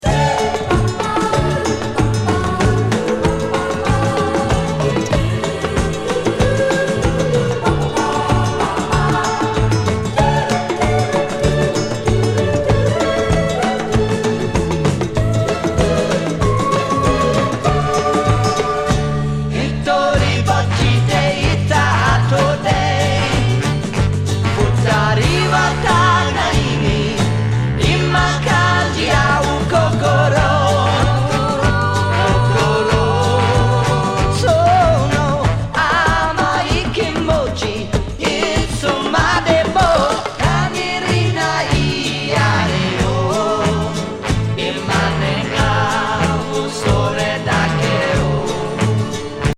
C/Wは日本語VER!セブンティーズ・ポップ。